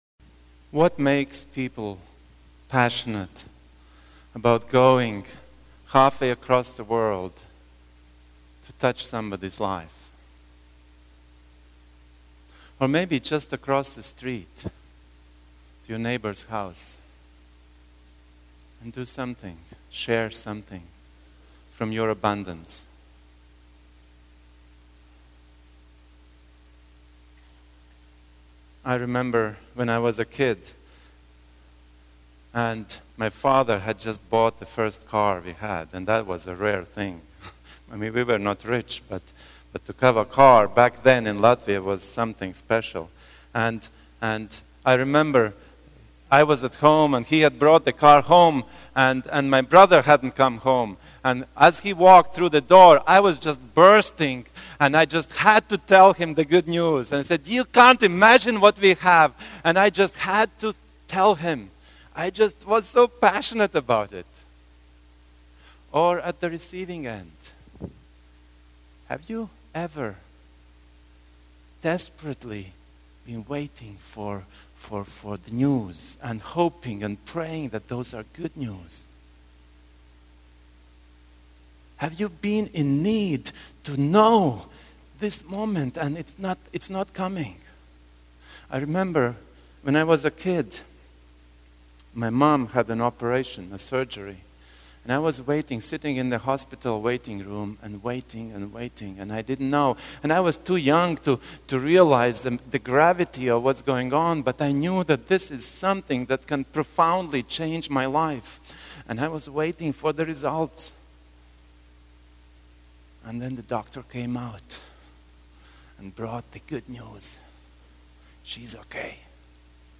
Sermons 2012